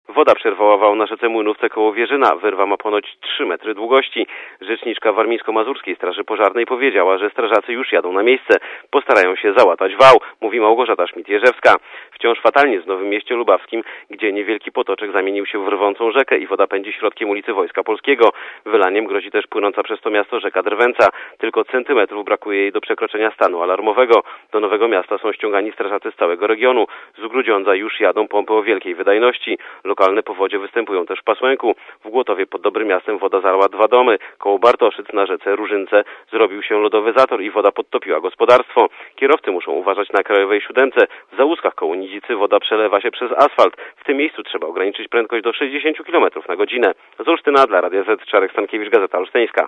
powodz_-_warmia_i_mazury.mp3